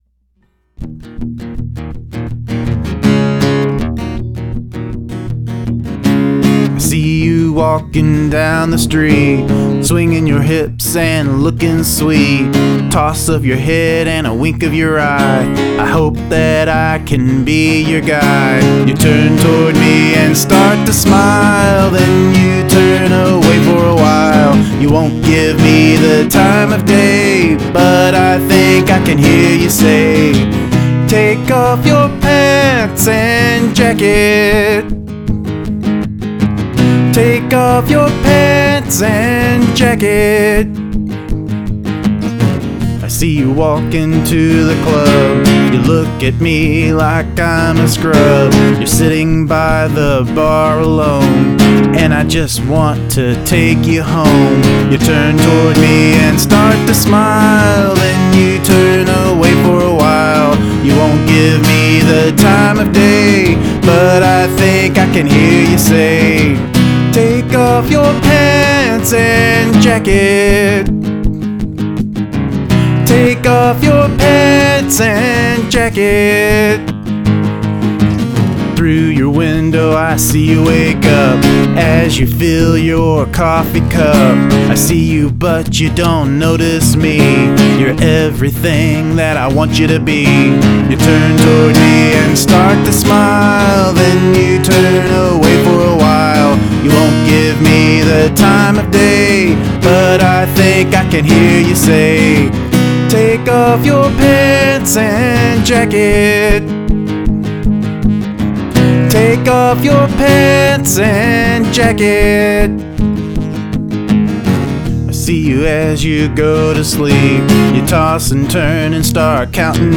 You done good here, bro - Maintaining the pace even into the halfway point.
Cool 60's sounding solo at the end too.